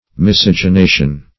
Miscegenation \Mis`ce*ge*na"tion\, n. [L. miscere to mix + the